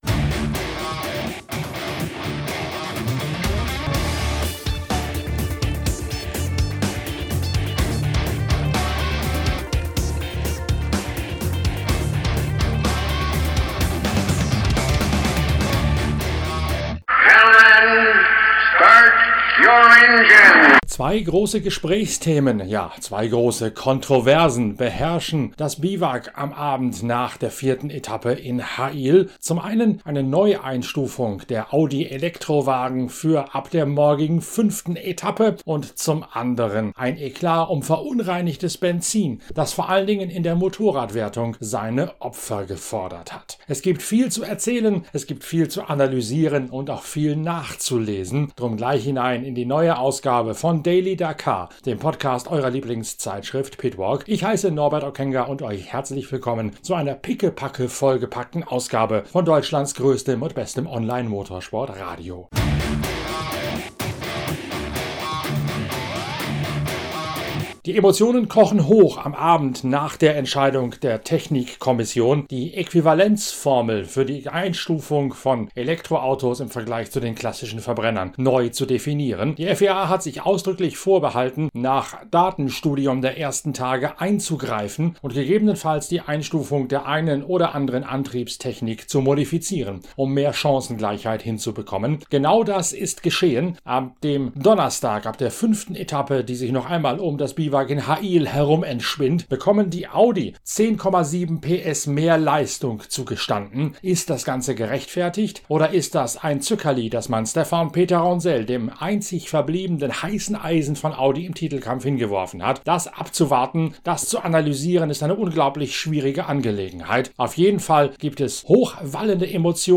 Dazu gibt es exklusive O-Töne von und Gespräche mit Nasser Al-Attiyah, Matthias Walkner